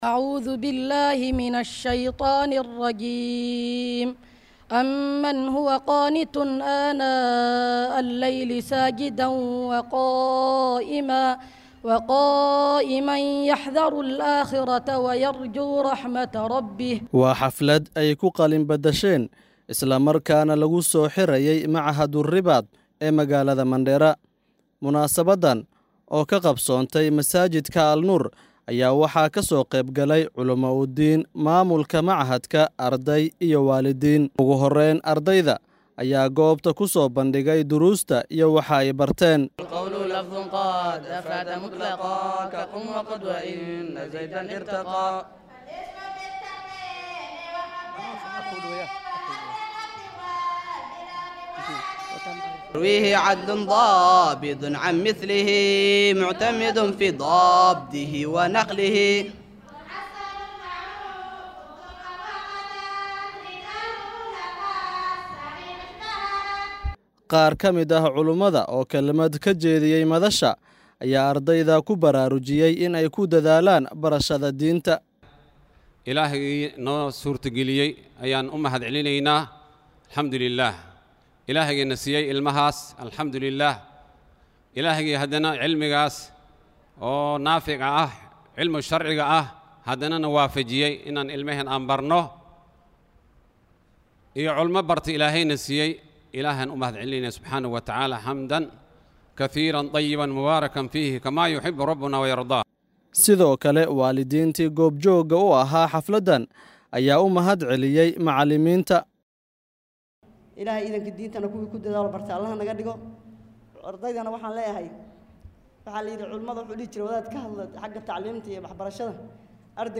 DHAGEYSO:Xaflad qalinjabin ah oo lagu qabtay Mandera